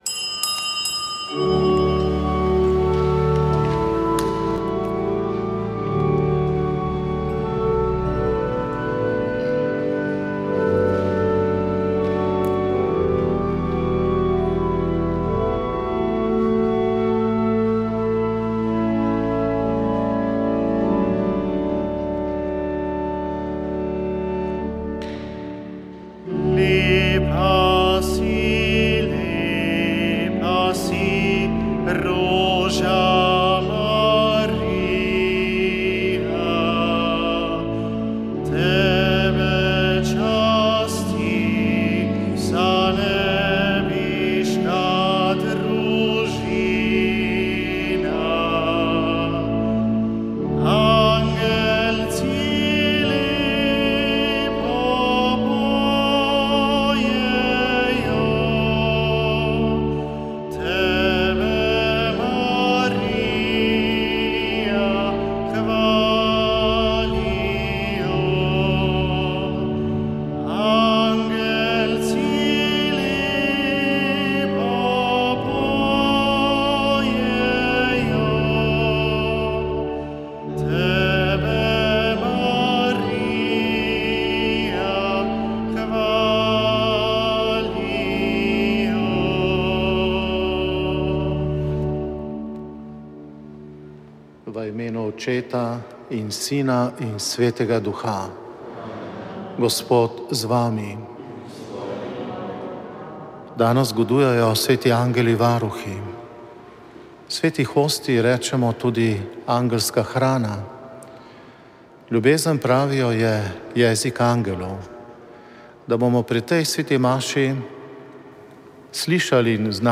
Sveta maša
Sv. maša iz iz kapele sv. Cirila in Metoda na Okroglem 3. 6.